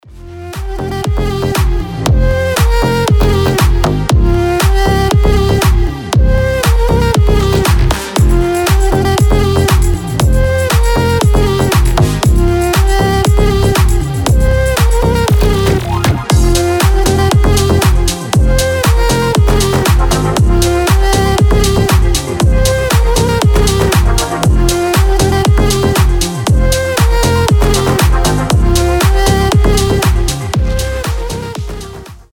громкие
EDM
мощные басы
без слов
качающие
Флейта
house
дудук
Шикарный дроп